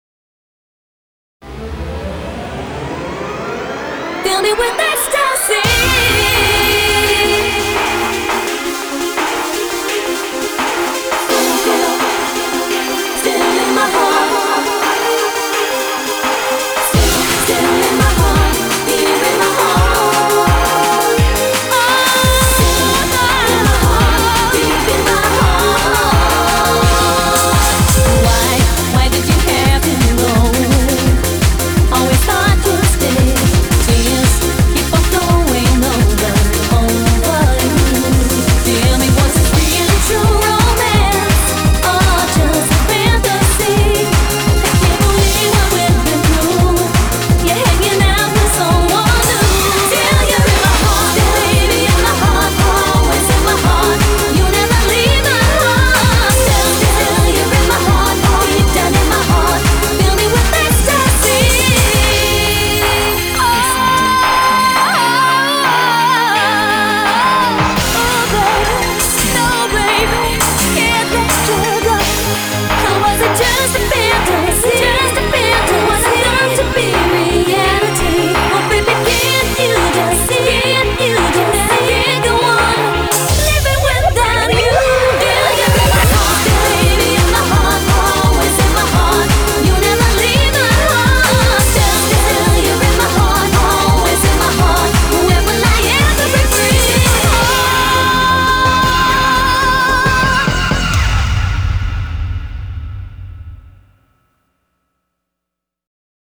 BPM85-170
Audio QualityMusic Cut